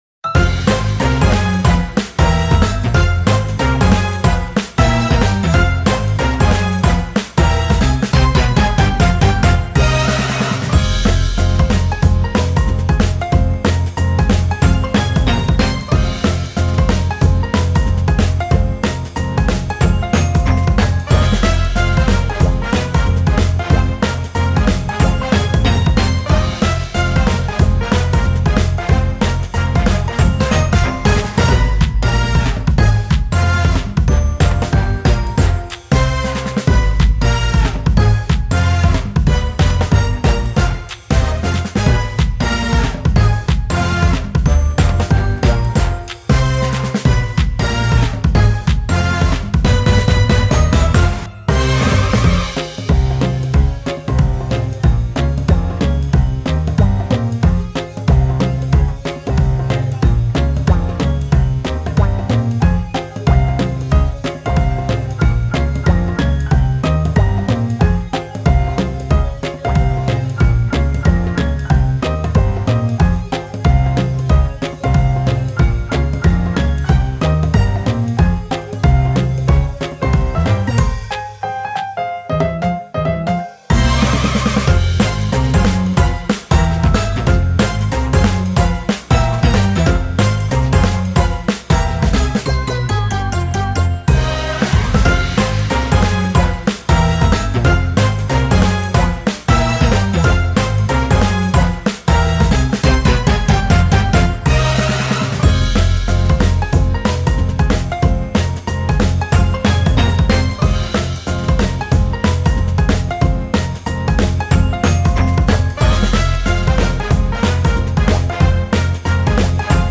トランペットなどの金管楽器が主体のゴージャスでおしゃれなジャズPOPです。
オープニングや幕開けなどの盛り上がりのある場面に合いそうな勢いと疾走感のある明るい曲となっています。
さらにはアップテンポな曲なのでダンスなどもつけることができるかも...!?
BPM 120
13. トランペット
17. ジャズ